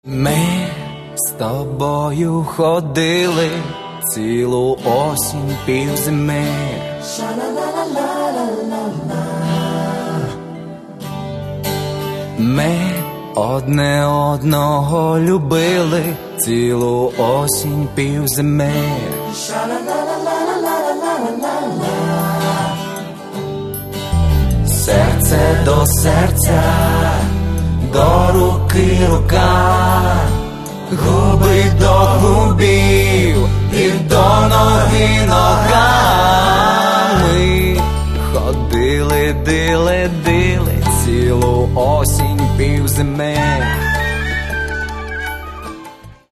Каталог -> Поп (Легкая) -> Сборники
синт-поп и неоромантика